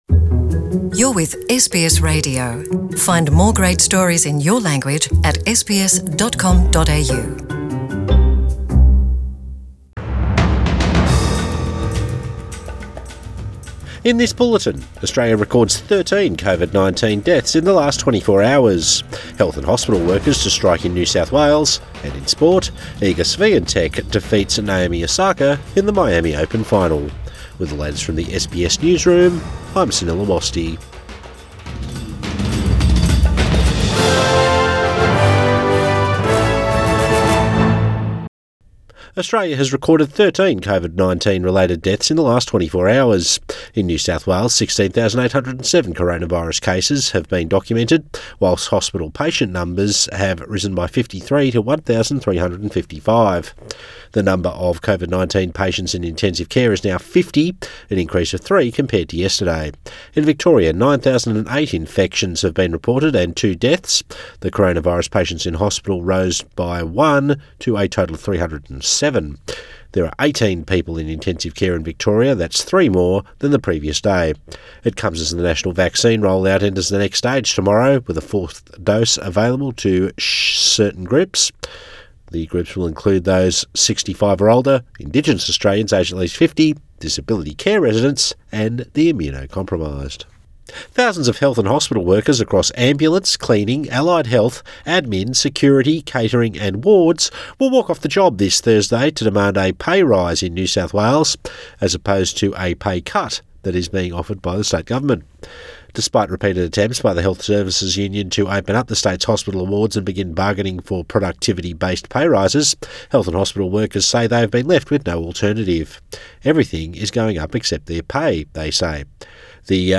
Midday Bulletin 3 April 2022